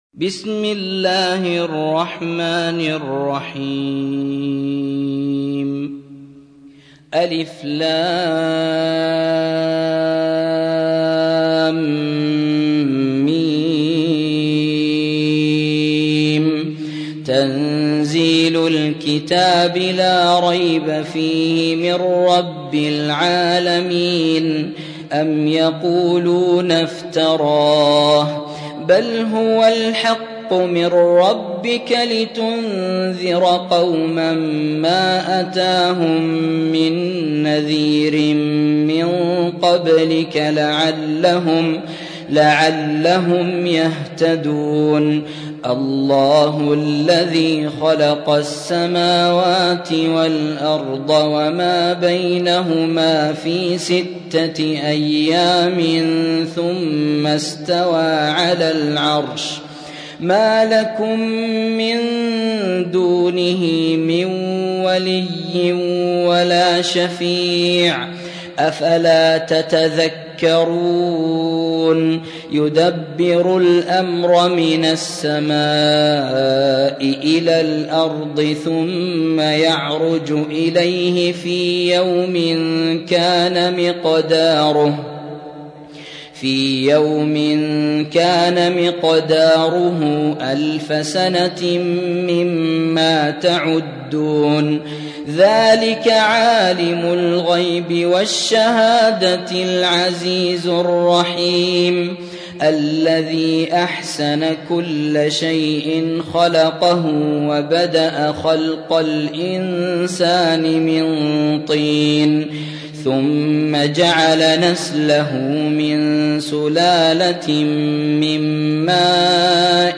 تحميل : 32. سورة السجدة / القارئ يوسف الشويعي / القرآن الكريم / موقع يا حسين